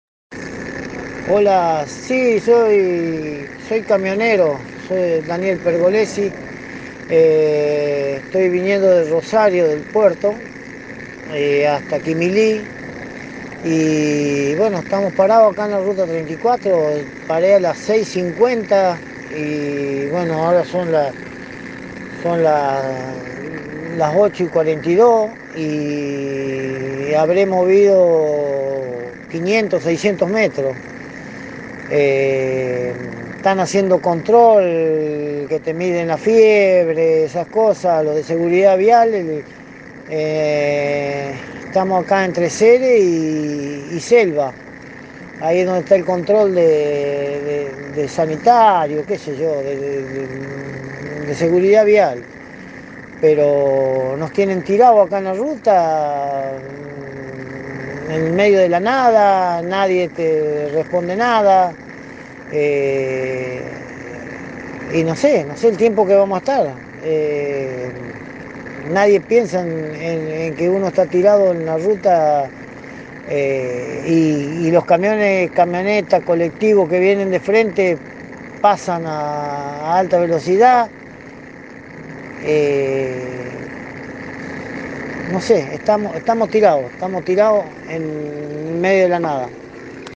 “Estamos tirados” relató un camionero a Radio Eme
camionero.mp3